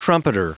Prononciation du mot trumpeter en anglais (fichier audio)
Prononciation du mot : trumpeter